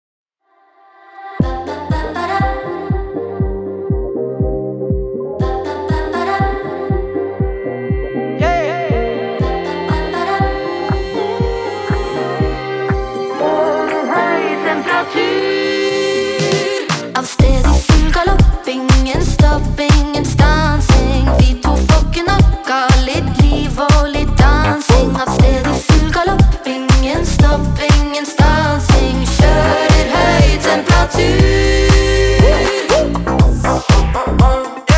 Жанр: R&B / Соул / Диско